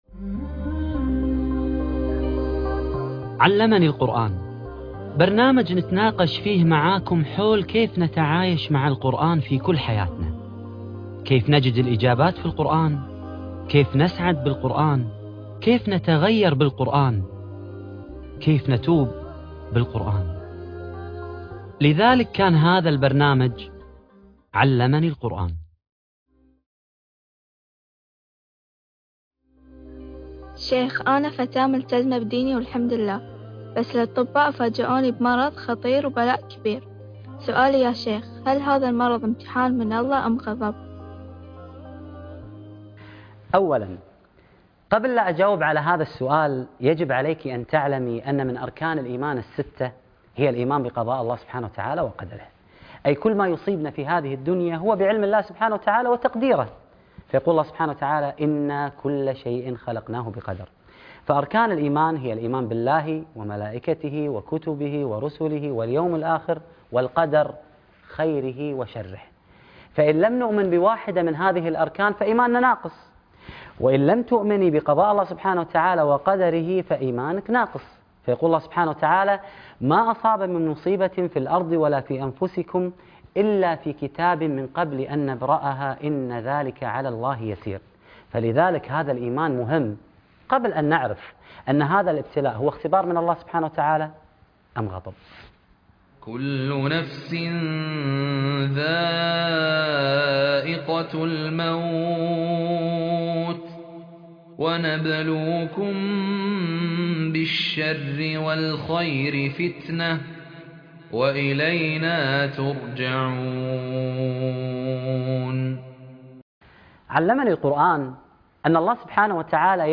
فهد الكندري برنامج علمنى القرآن الحلقة 1 - القاريء فهد الكندري